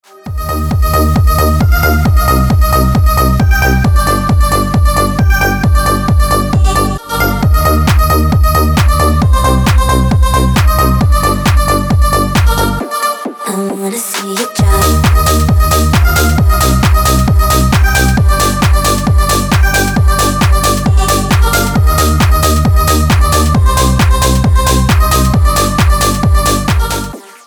Клубные рингтоны
рингтон клубного стиля